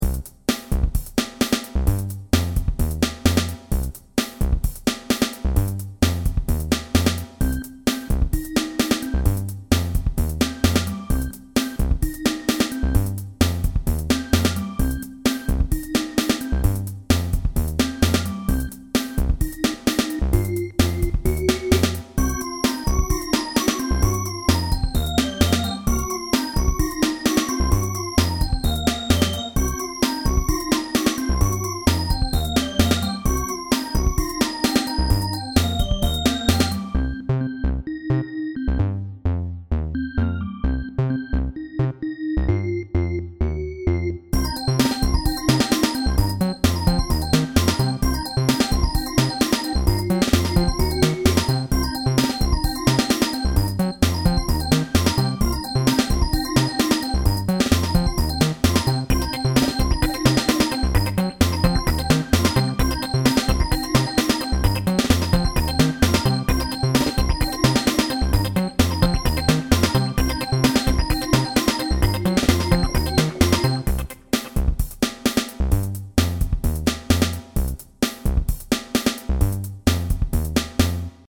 Título Funk
Descriptores funk música